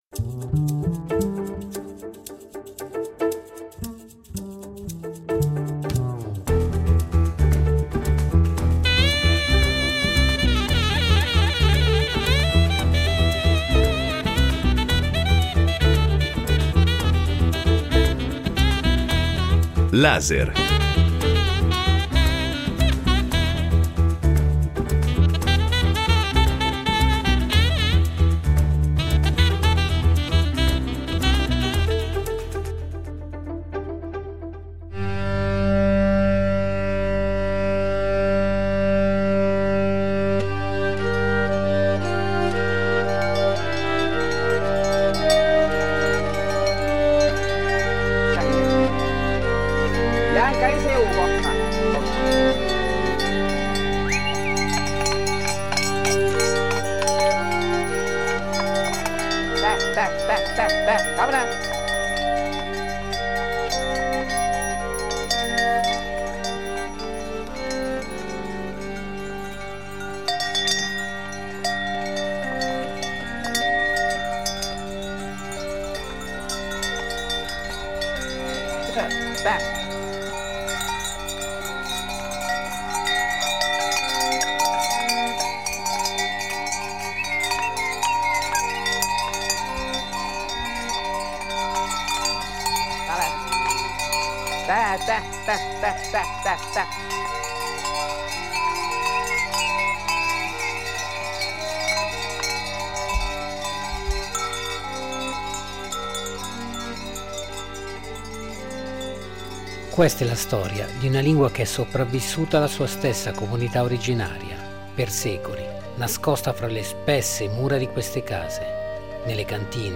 Da qui parte un racconto di tradizione orale che si dipana nel tempo fino ad arrivare al XII° secolo.